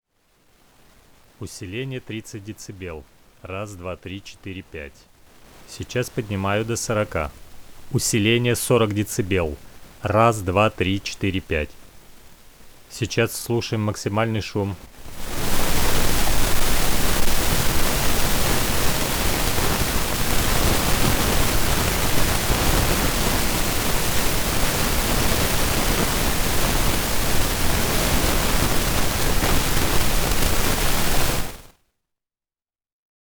Прошу владельцев МК-012, столкнувшихся с подобной проблемой и определивших её причину, поделиться опытом. Звук потрескивания в записанном сигнале.